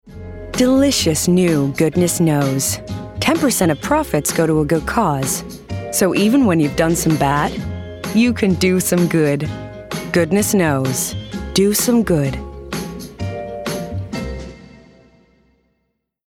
***NEW ARTIST*** | 20s-30s | Transatlantic, Genuine & Charismatic
Goodness Knows (US)